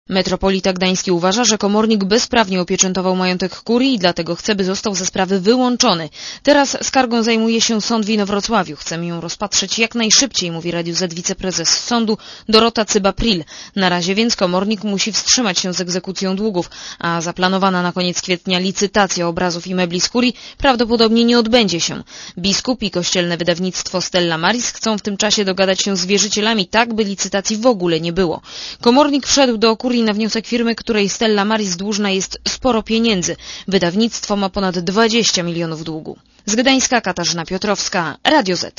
Posłuchaj relacji reporterki Radia ZET (150 KB)